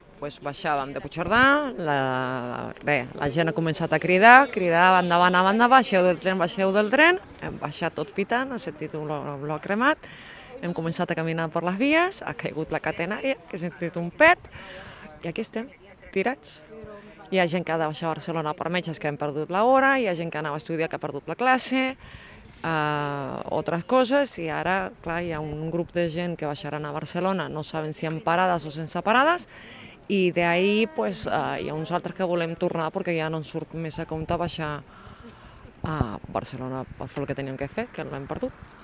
Una passatgera afectada per l’incendi a l’R3: “Hem sentit olor de cremat i hem baixat pitant del tren” ( Àudio 3 )